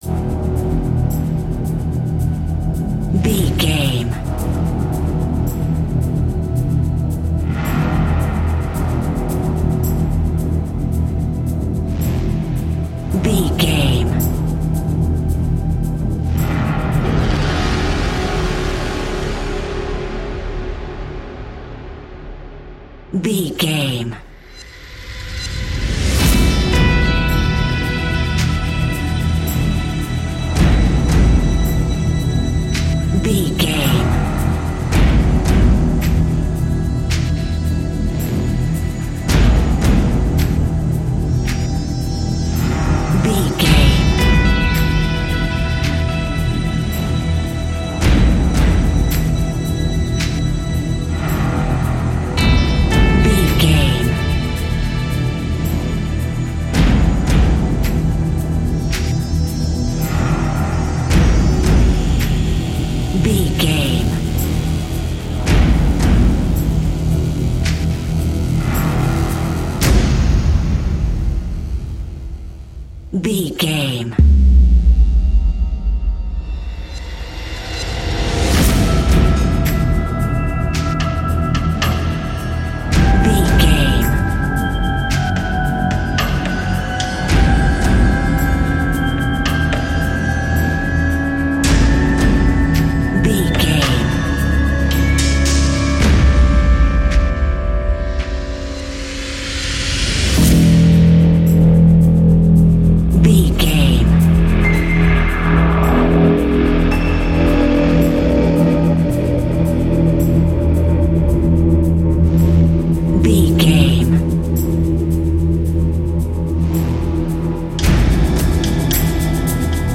Thriller
Ionian/Major
E♭
industrial
dark ambient
synths